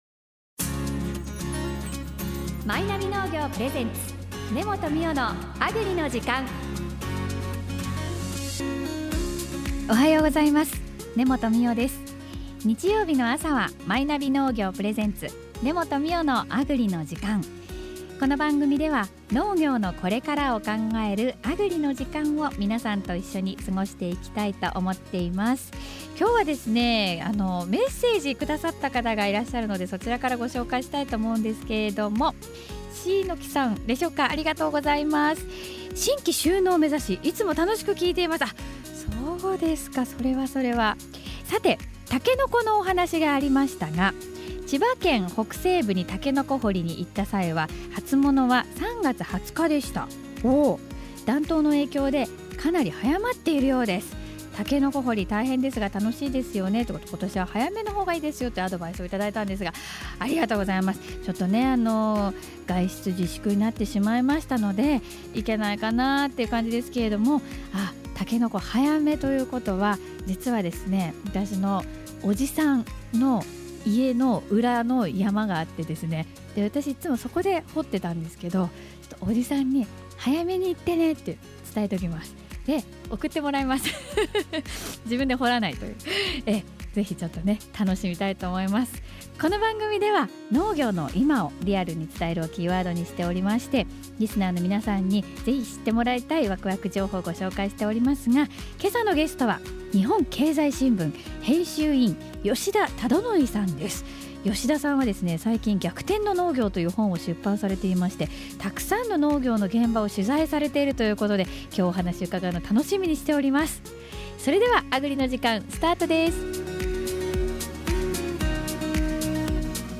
お電話でご出演いただきました。